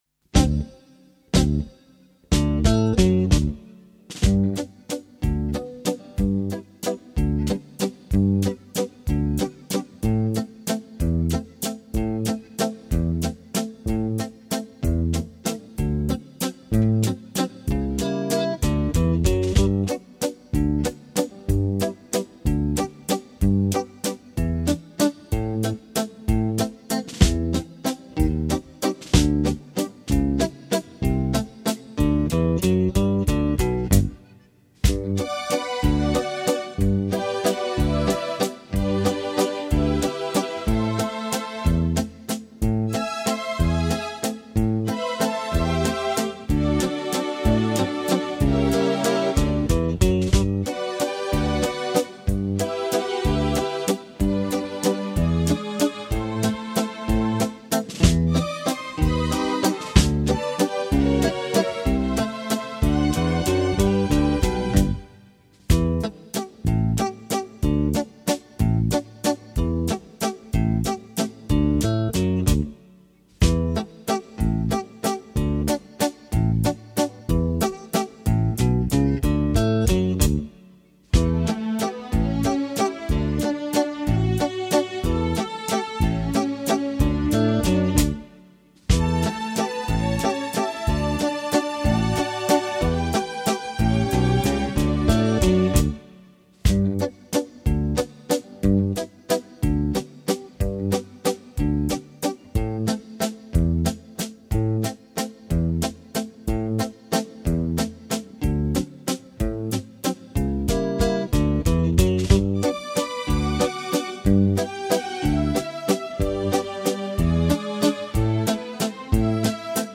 VALZER musette